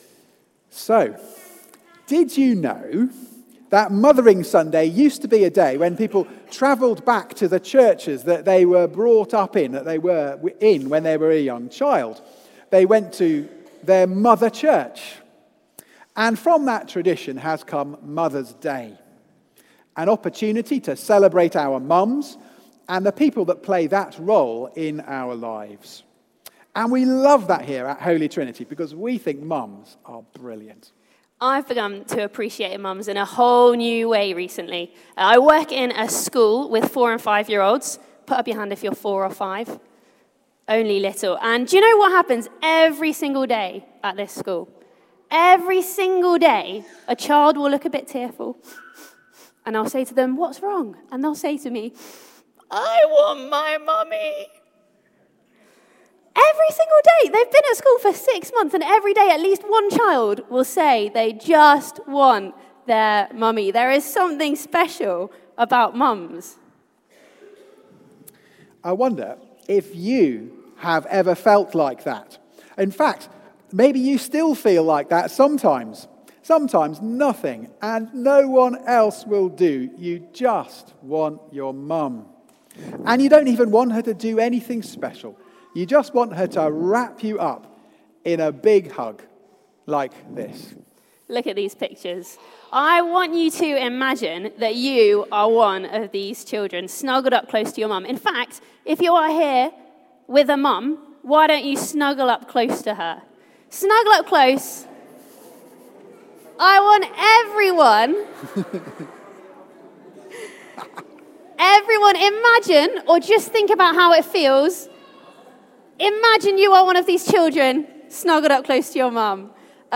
Series: Psalms Book One: The Prayers of God's King Theme: Trusting like a Child with their Mother Sermon